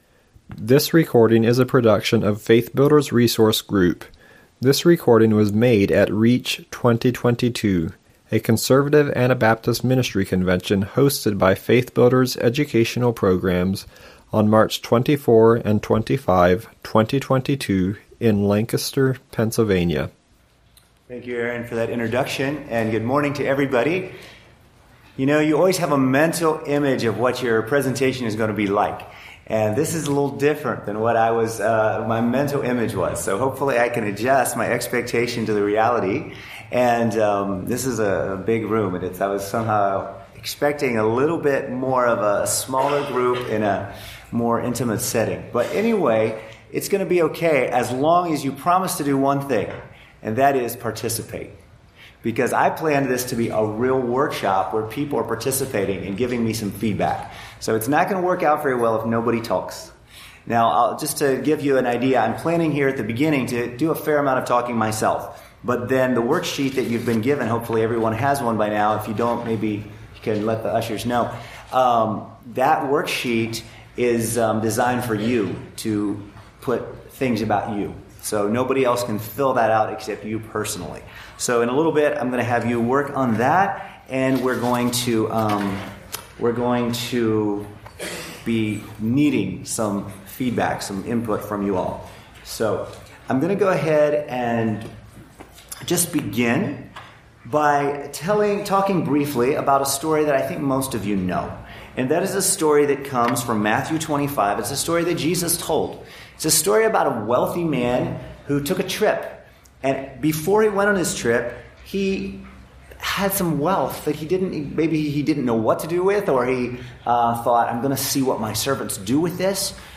This session will consider answers to those questions. Don't Hide Your Talents: How to Prepare for a Lifetime of Service was presented at REACH 2022, on March 24-25, 2022, at Calvary Church in Lancaster, Pennsylvania.